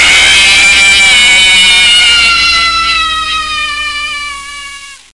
Woman In Pain Sound Effect
Download a high-quality woman in pain sound effect.
woman-in-pain.mp3